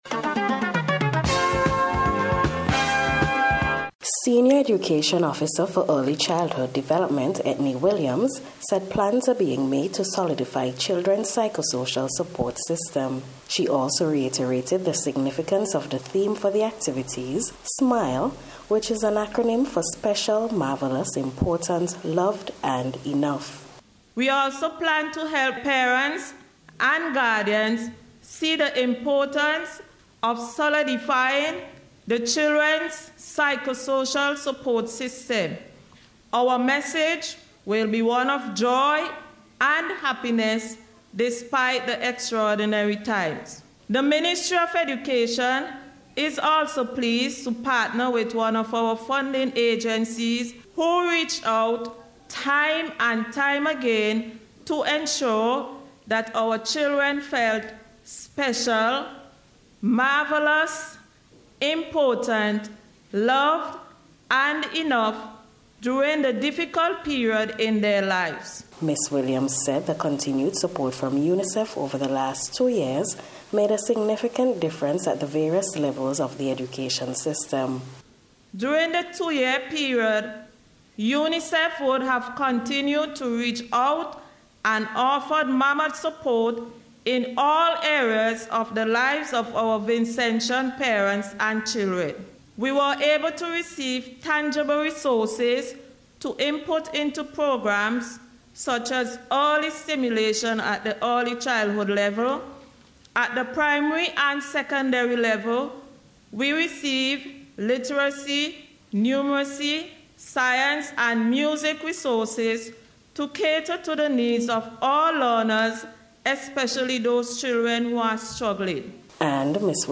NBC’s Special Report for May 3rd 2022